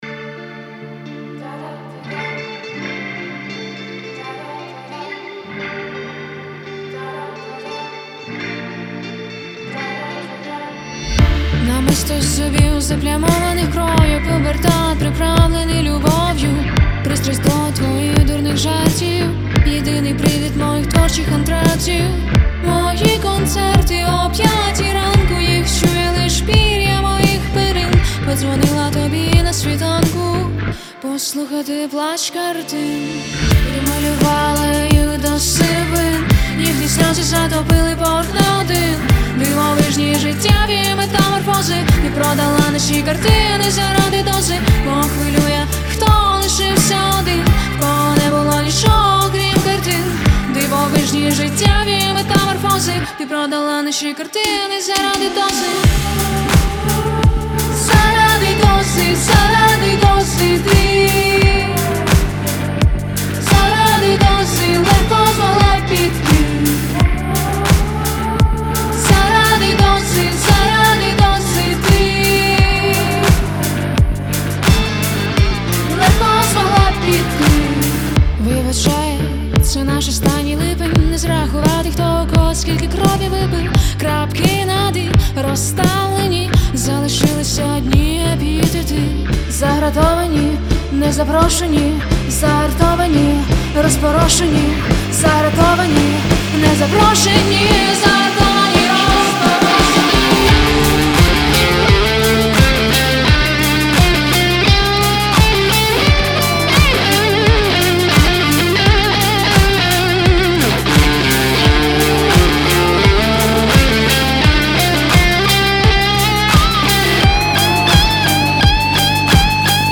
• Жанр: Pop, Rock